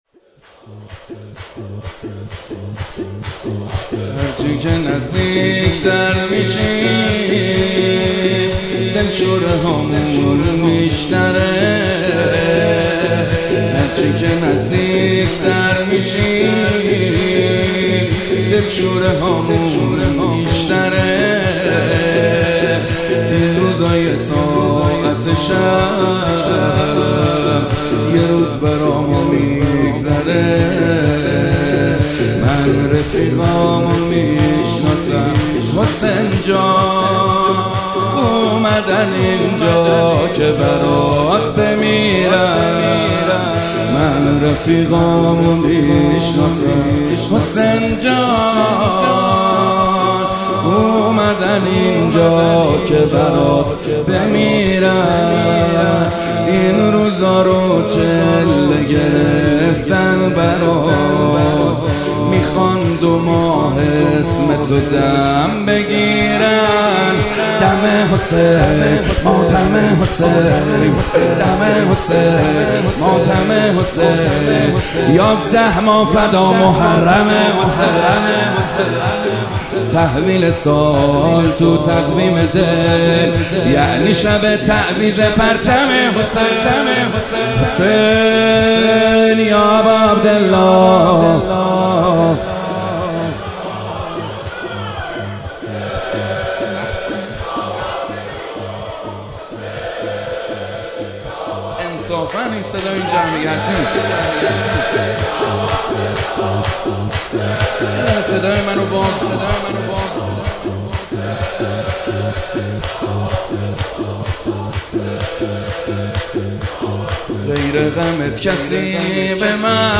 مداحی به سبک شور اجرا شده است.